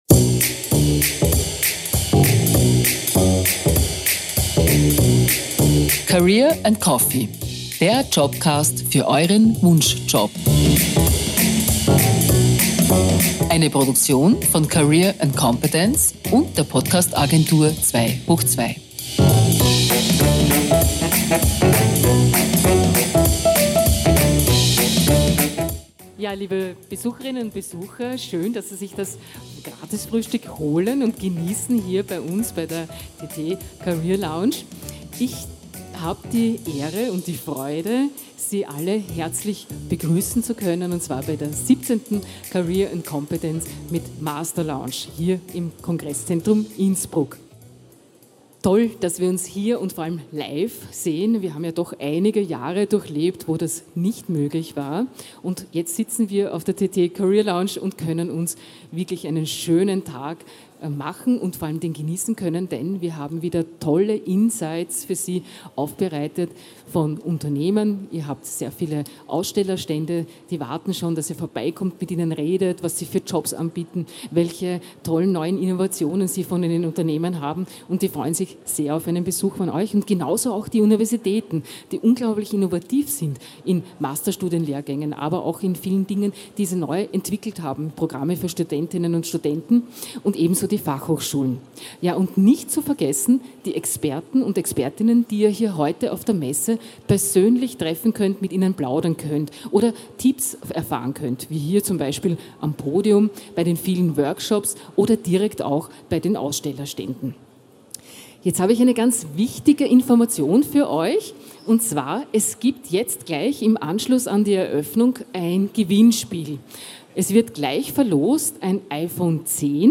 Livemitschnitt von der career & competence 2023 in Innsbruck, am 26. April 2023.